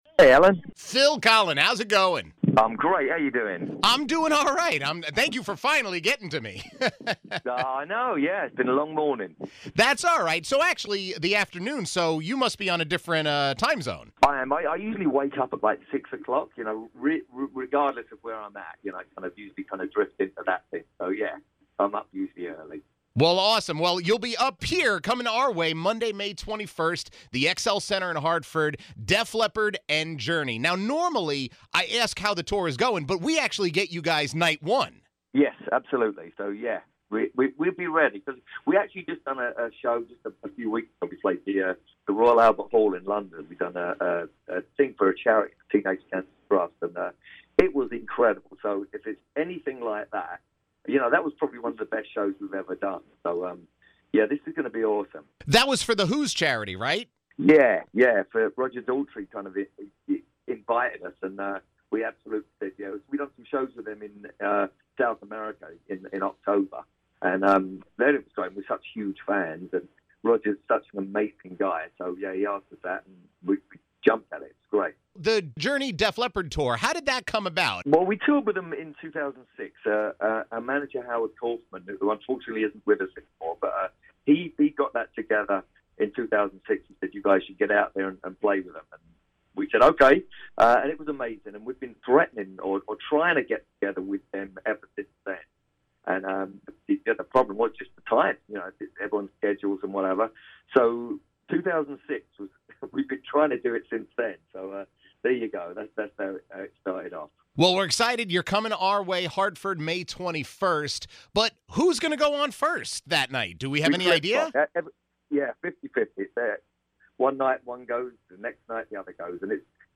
chats with Def Leppard guitarist Phil Collen about his busy schedule